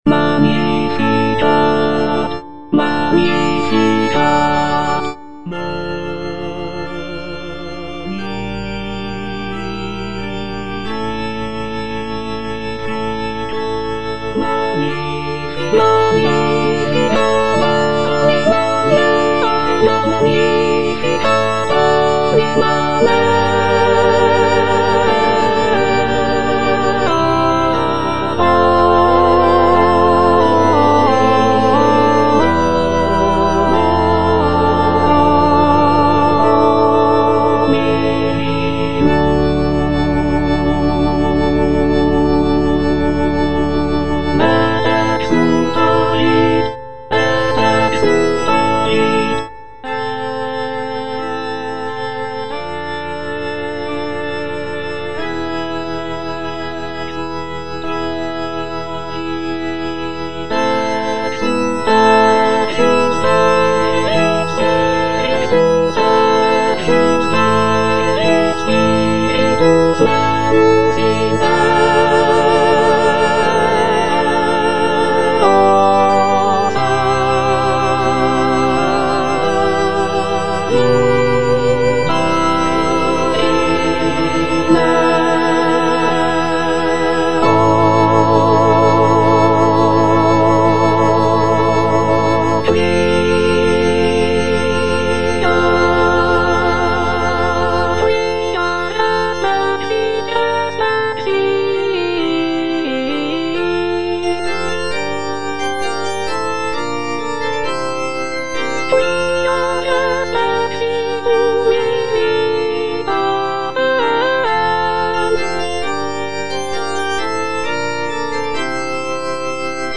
Soprano II (Emphasised voice and other voices) Ads stop
is a sacred choral work